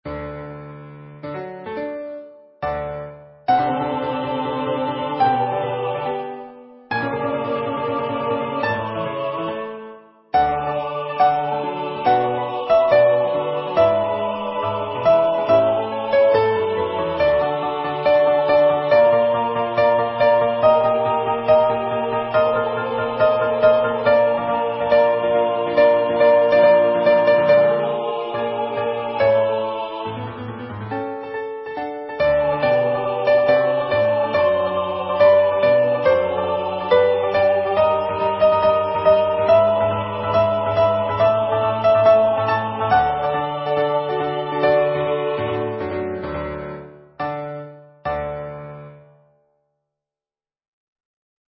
Practice files: Soprano:
Number of voices: 4vv   Voicing: SATB
Genre: SacredMass
Instruments: Piano